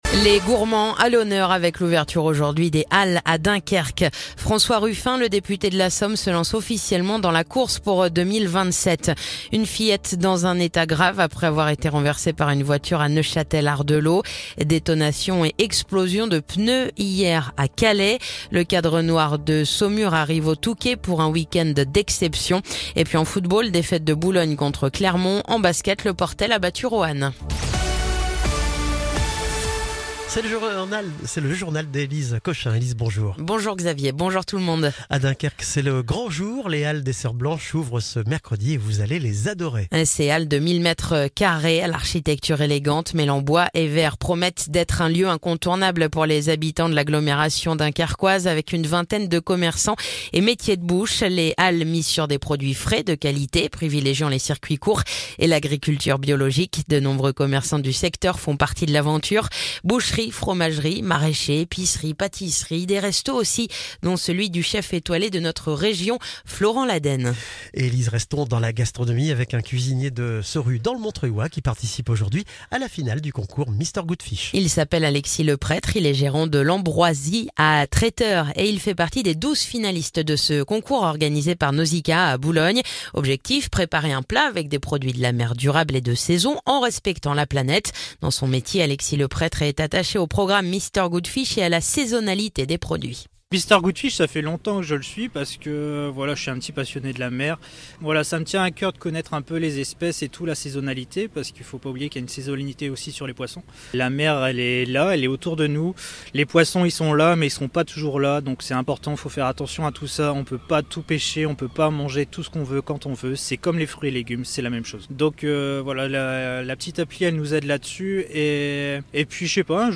Le journal du mercredi 21 mai